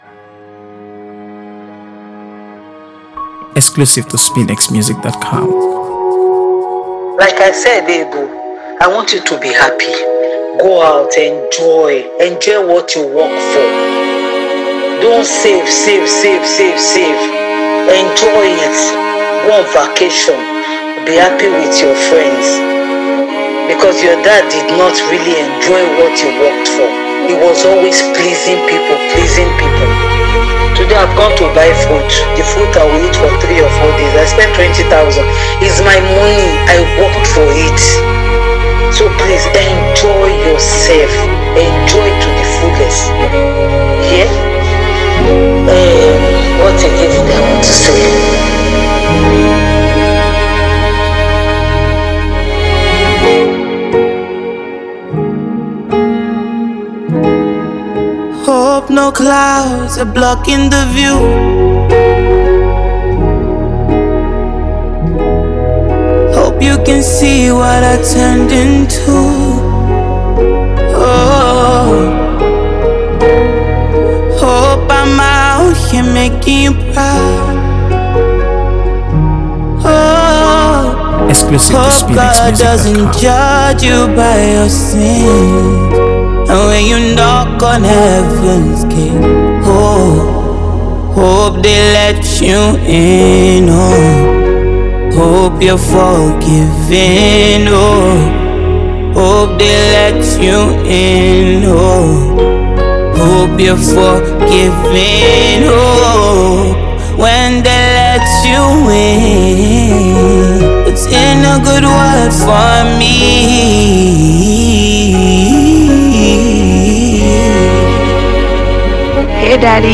AfroBeats | AfroBeats songs
Nigerian singer-songwriter
With her signature style and captivating vocals